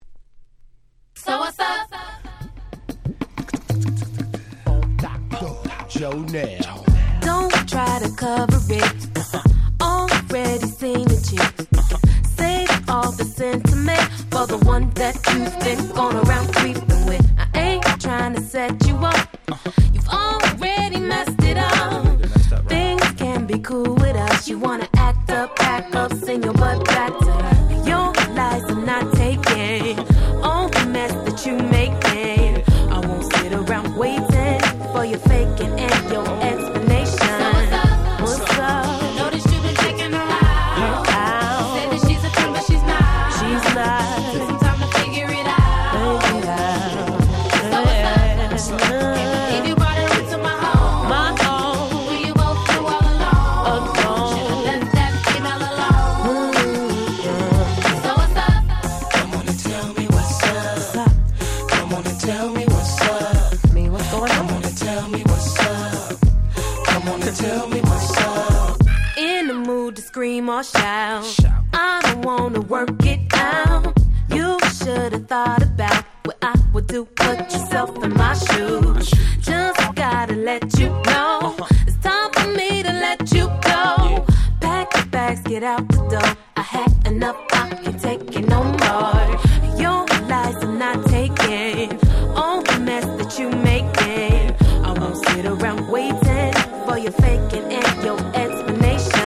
03' Nice R&B / Neo Soul !!